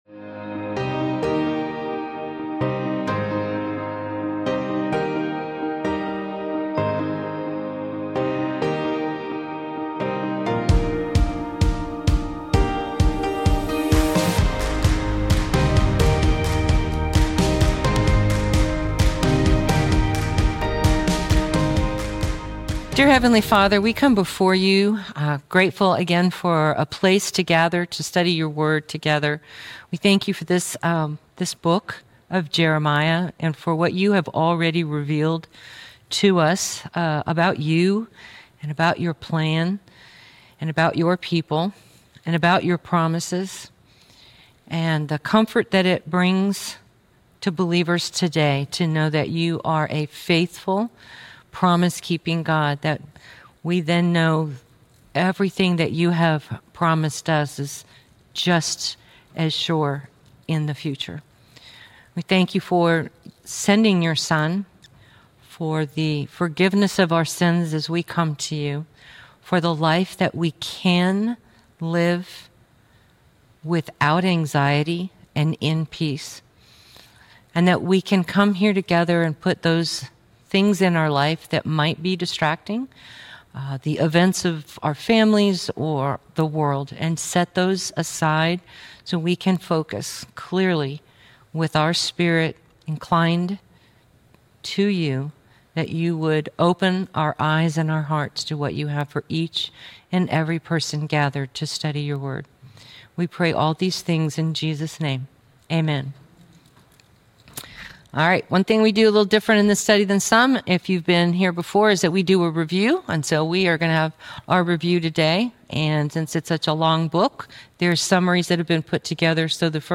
Jeremiah - Lesson 49B | Verse By Verse Ministry International